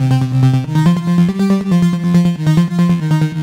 Index of /musicradar/french-house-chillout-samples/140bpm/Instruments
FHC_Arp B_140-C.wav